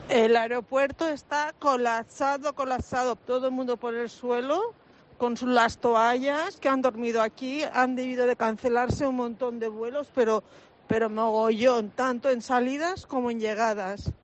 El aeropuerto está colapsado: el testimonio de una viajera atrapada en el aeropuerto de Palma